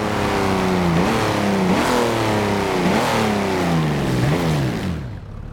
throttle_off.wav